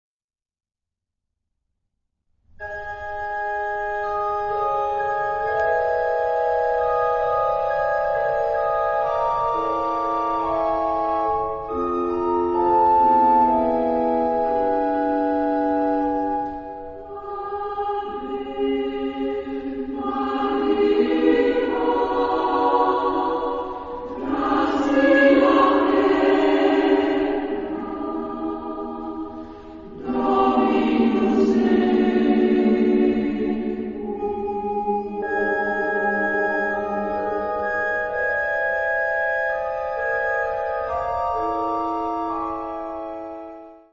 Genre-Style-Forme : Sacré ; Prière ; Motet
Caractère de la pièce : moderne
Type de choeur : SSAA  (4 voix égales de femmes )
Instrumentation : Orgue  (1 partie(s) instrumentale(s))
Tonalité : atonal